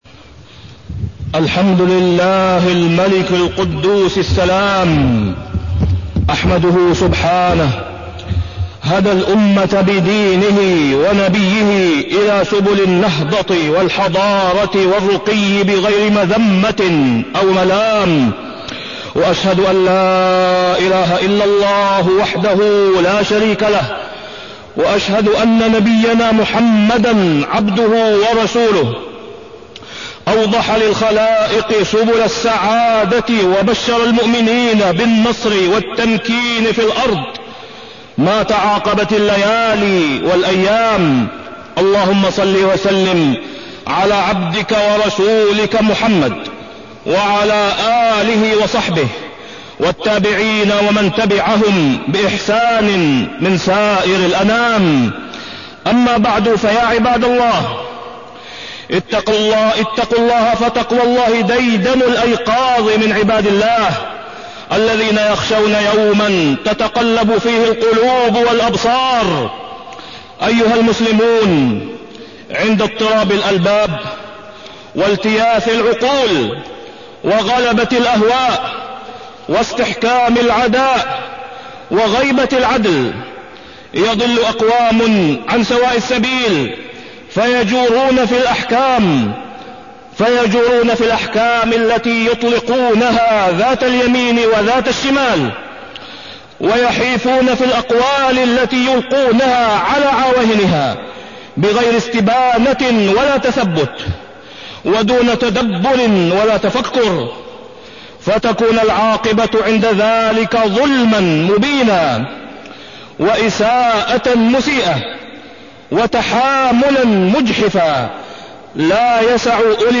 تاريخ النشر ٣٠ صفر ١٤٢٠ هـ المكان: المسجد الحرام الشيخ: فضيلة الشيخ د. أسامة بن عبدالله خياط فضيلة الشيخ د. أسامة بن عبدالله خياط الحضارة الإسلامية